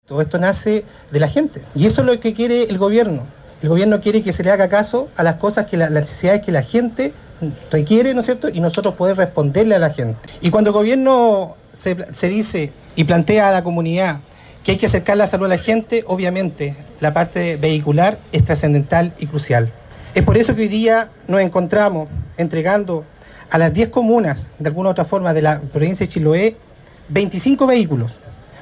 La entrega de las máquinas tuvo lugar en la plaza de Castro, donde se dieron cita autoridades regionales, provinciales y comunales, además de profesionales del ámbito de la salud.